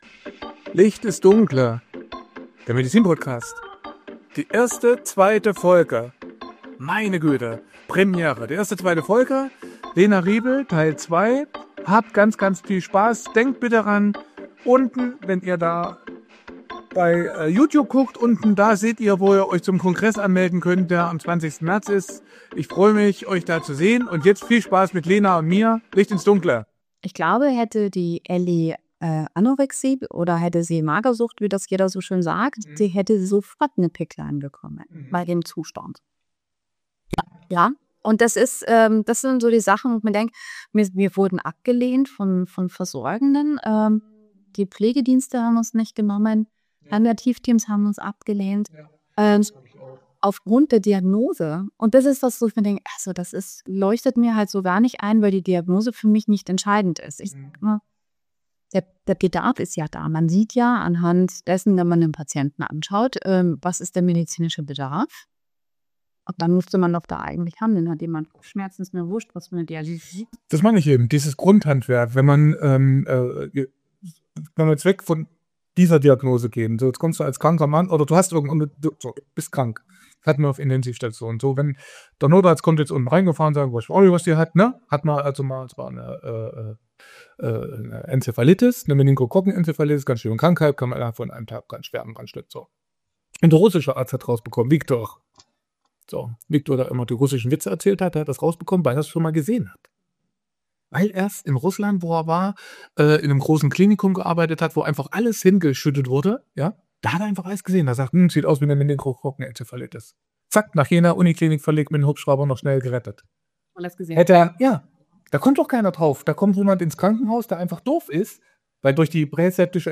Abgelehnt trotz Bedarf: Wie Diagnosen Versorgung verhindern können | Interview